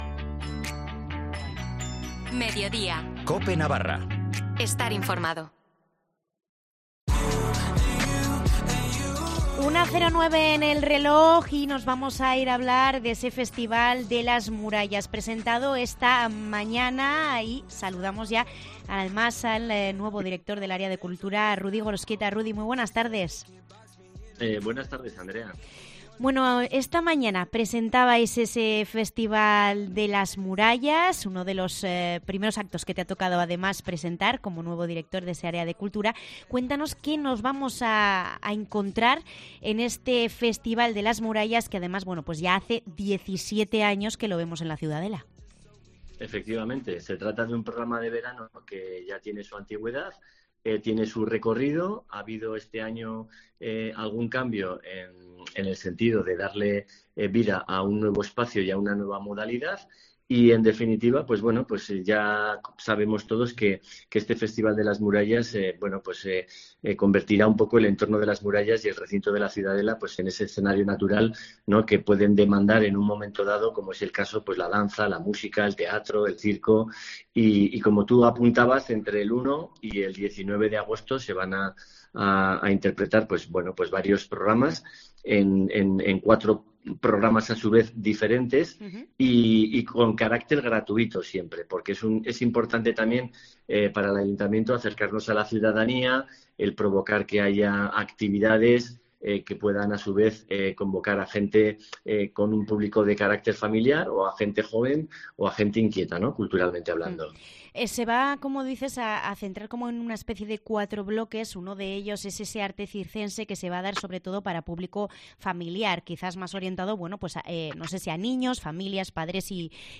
nos presenta el programa en Cope Navarra